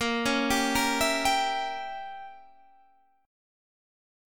Bbdim7 Chord
Listen to Bbdim7 strummed